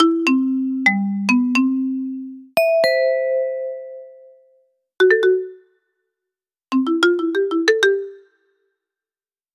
Gong-Kit drahtlos RW=150m weiss
Funk-Gong mit Klingeltaster – Weiß Der unkomplizierte und zuverlässige Funk-Gong DC311S von Honeywell Home ist vorkonfiguriert, einfach zu installieren und...